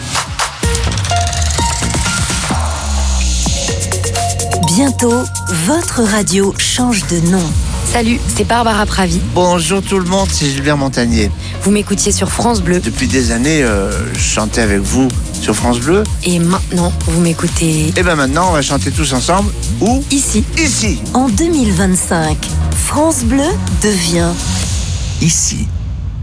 Ook valt de term Ici steeds meer in de vormgeving en zijn er promo’s te horen om luisteraars te informeren over de veranderingen.
Promo-France-Bleu-wordt-Ici.mp3